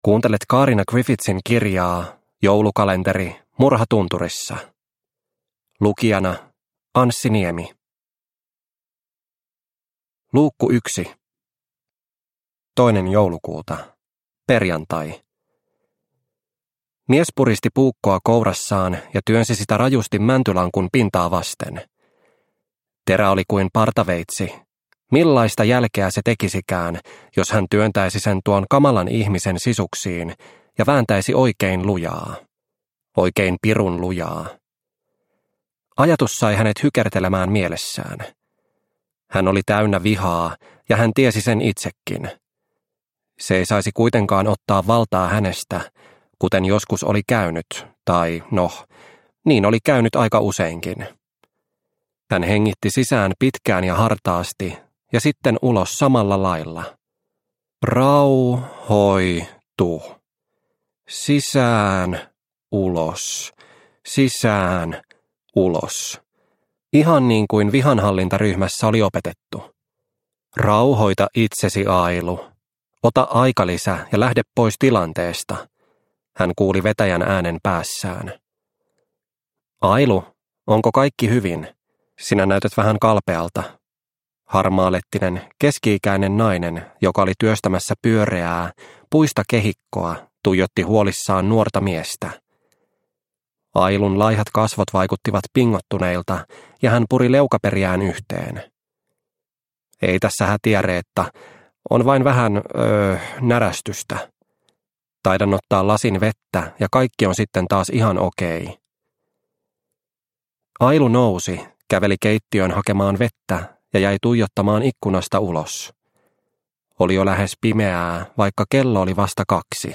Murha tunturissa - Osa 1 – Ljudbok – Laddas ner